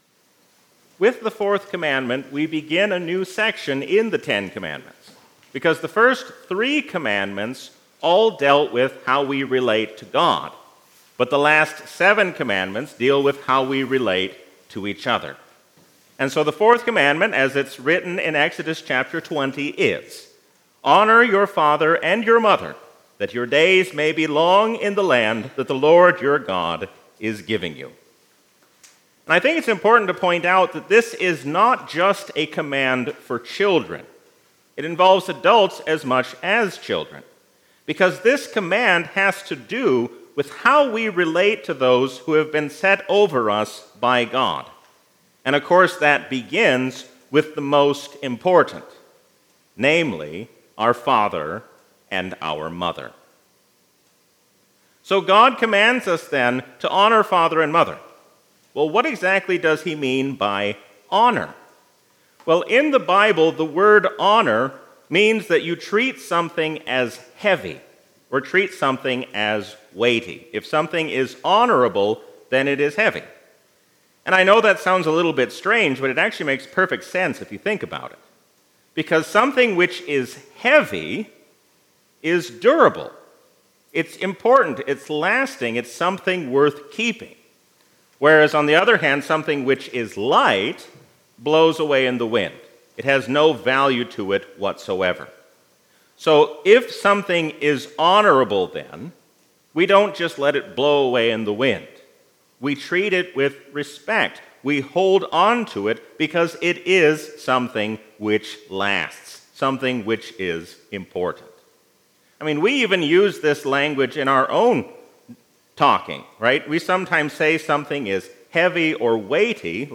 A sermon from the season "Epiphany 2023." God wants us to use His name for good and not for evil.